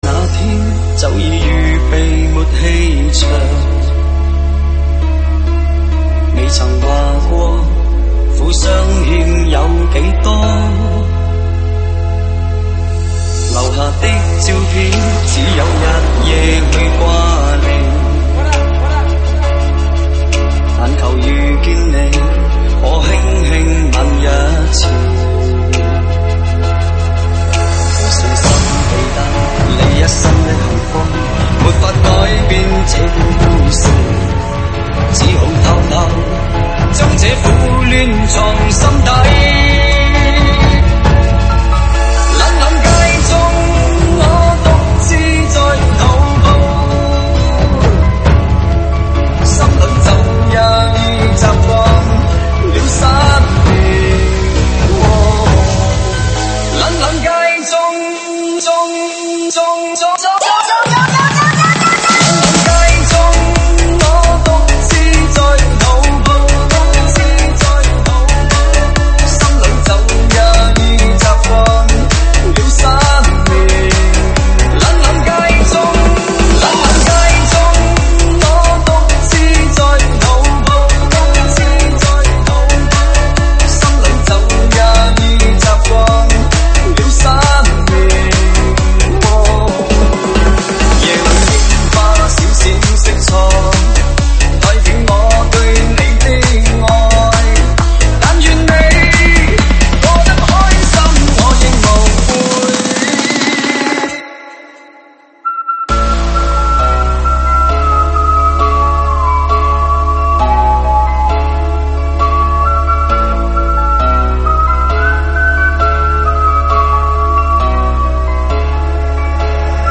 [现场串烧]
舞曲类别：现场串烧